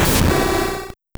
Cri de Démolosse dans Pokémon Or et Argent.